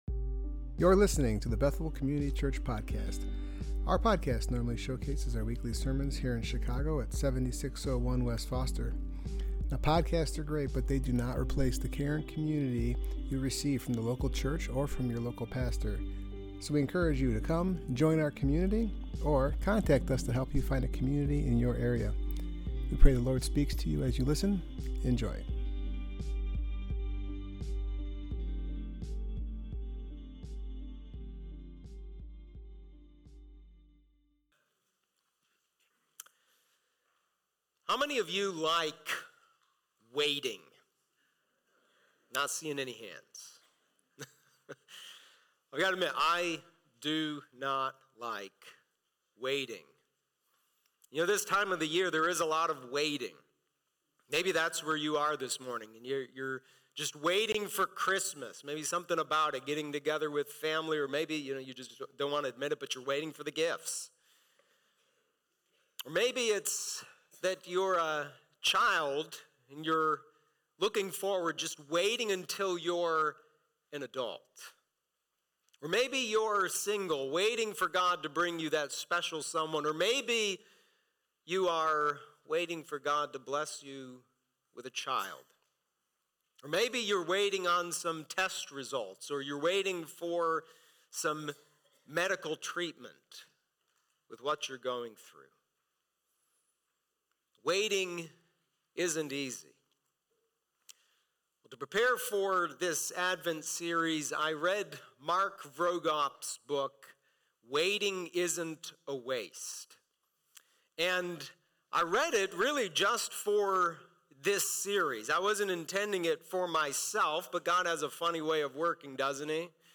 The Weary World Rejoices Passage: Luke 2:25-35 Service Type: Worship Gathering Topics